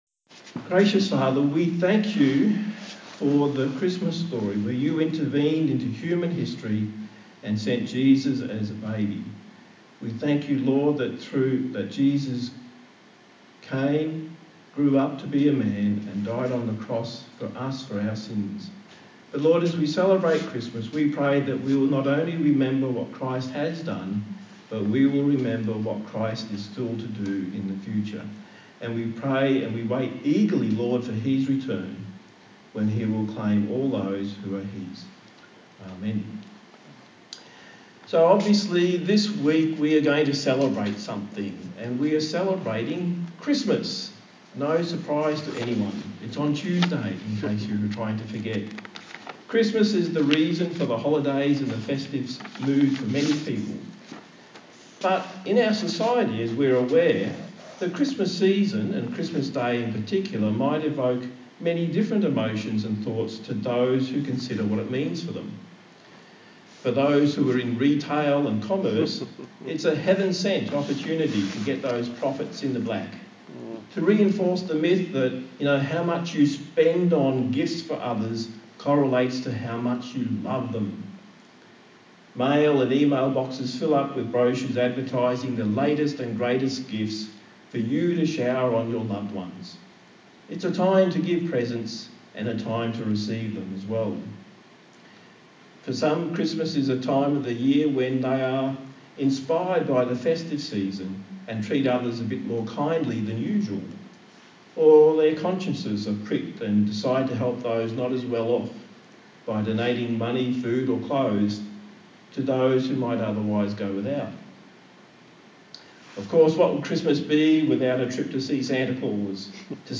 A sermon on the book of Luke